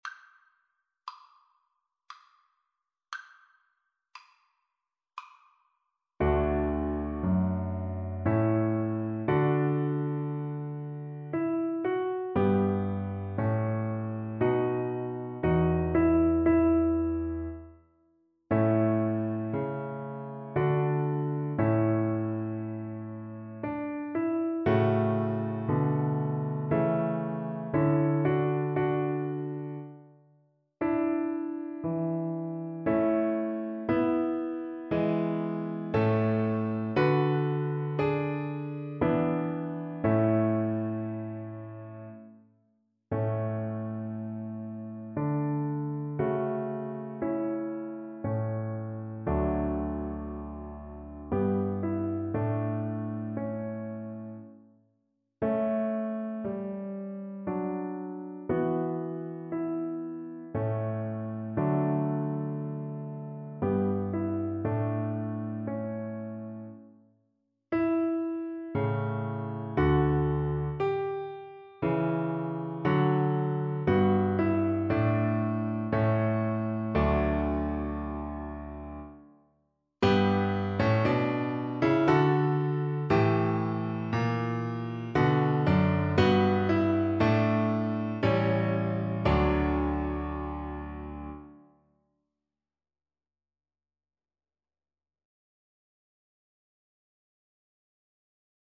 Cello
D major (Sounding Pitch) (View more D major Music for Cello )
3/4 (View more 3/4 Music)
Feierlich, doch nicht zu langsam = 76